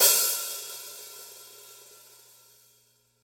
Sizzly Open High-Hat A# Key 48.wav
Royality free open hat one shot tuned to the A# note. Loudest frequency: 8396Hz
sizzly-open-high-hat-a-sharp-key-48-LPd.mp3